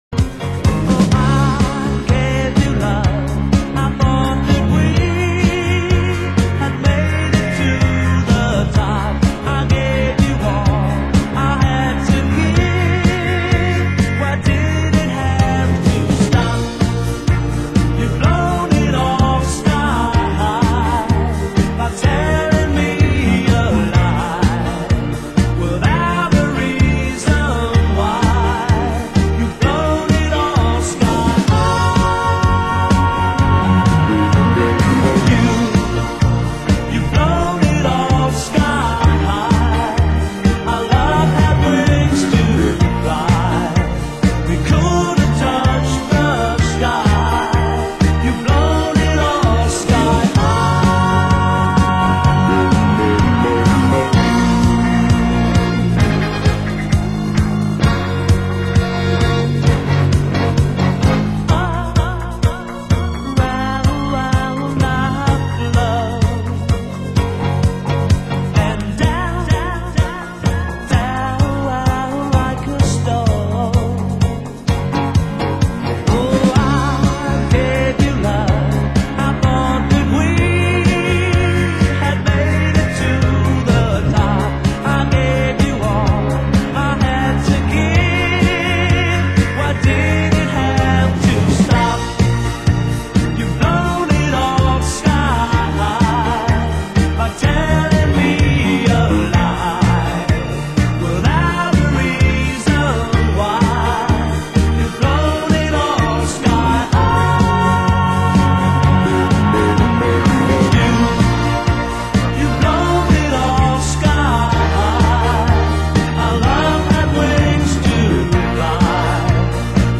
Genre: Disco